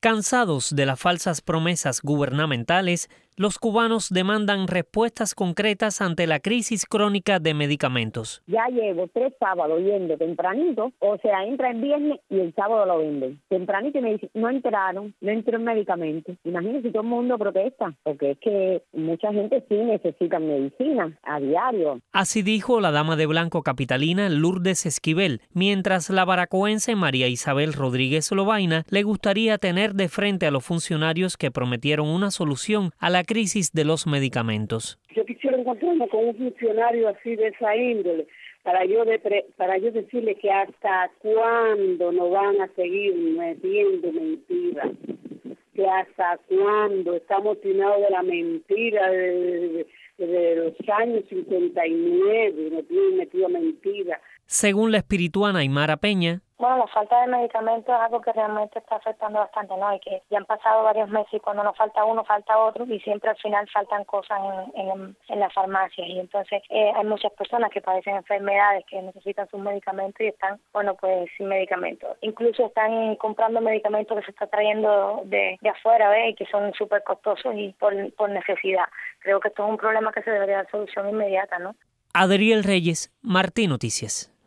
En un sondeo realizado por Martí Noticias, las opiniones en diferentes puntos del país muestran la frustración de los afectados.